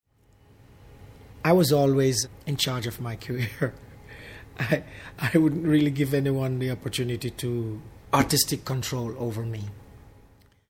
Cliff smiled, paused and quoted a few lines from the song My Way.